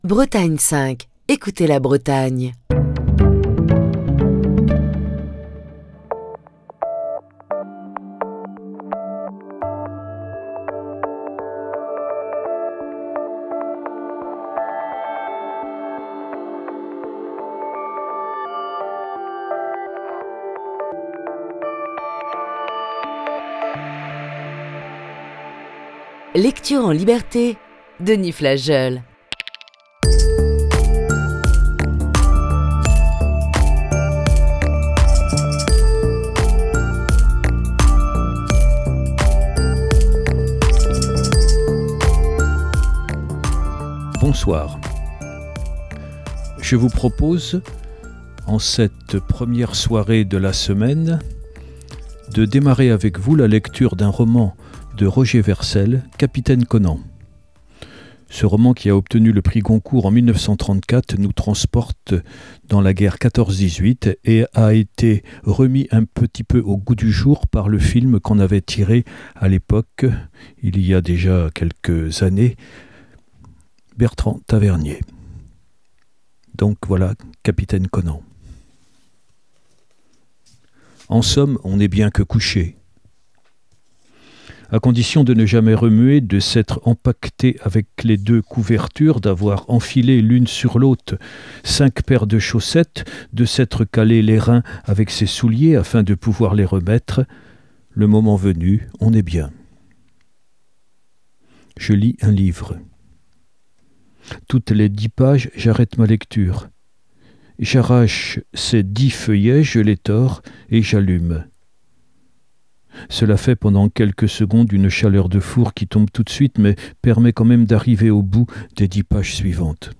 Émission du 9 mai 2022.